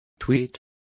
Complete with pronunciation of the translation of tweets.